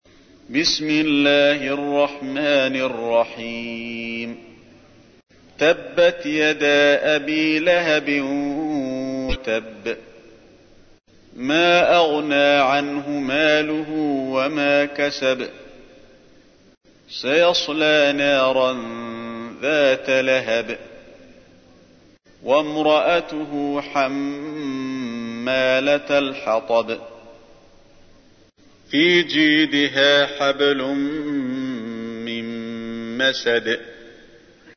تحميل : 111. سورة المسد / القارئ علي الحذيفي / القرآن الكريم / موقع يا حسين